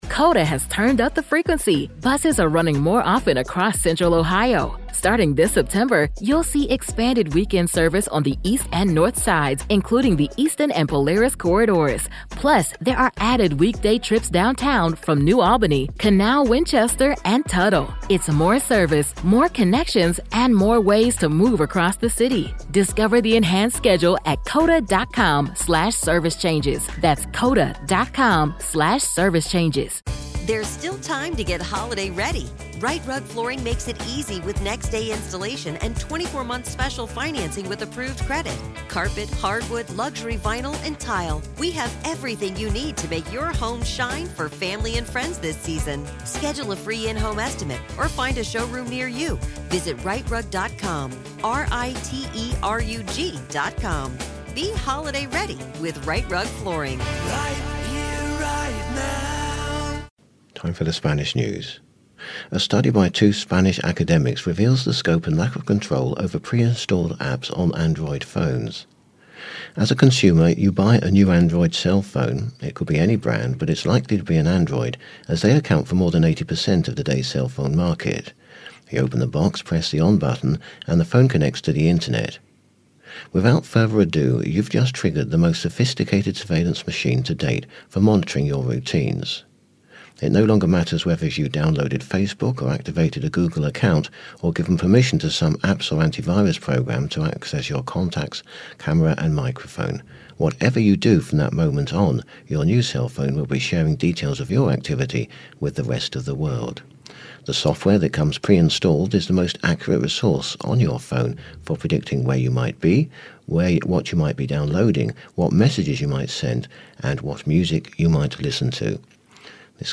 The latest Spanish News Headlines in English